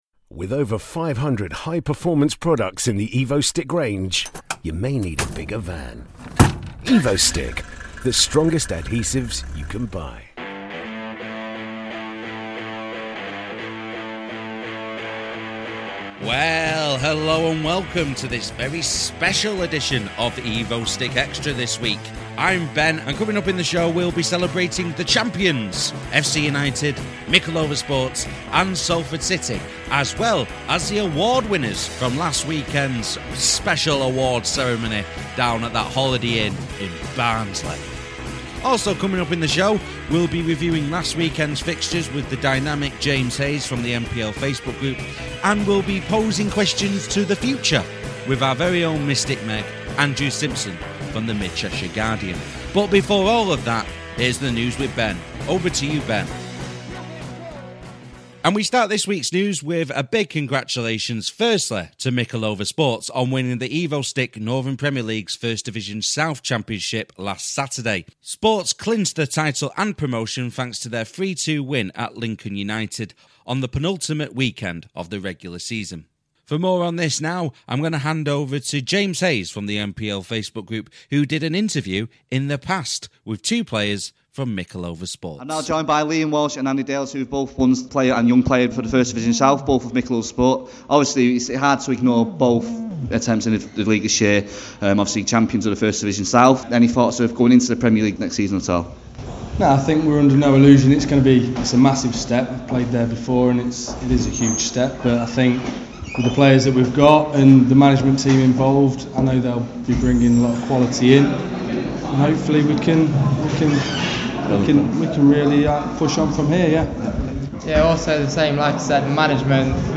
We also have an interview with player of the season